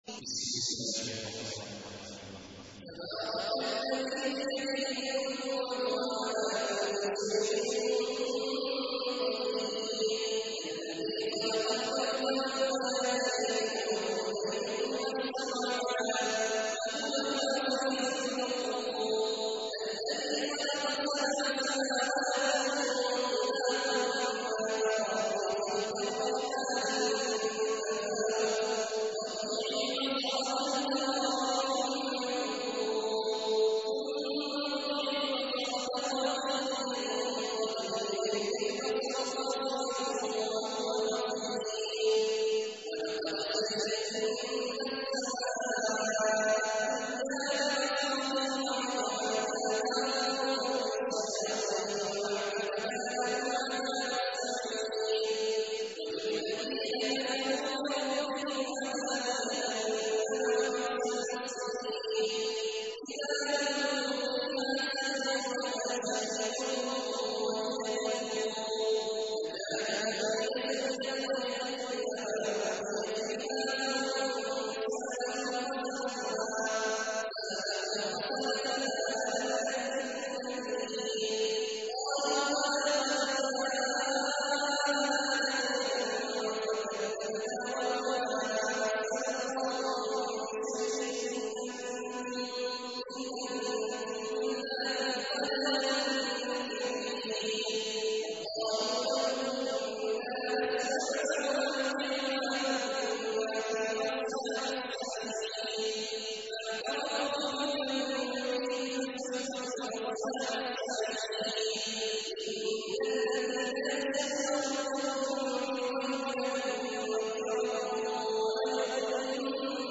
إليكم هذه التلاوة الرائعة لصاحب الصوت الندي المتميز :
الشيخ / عبد الله بن عوّاد الجهني
( إمام المسجد النبوي )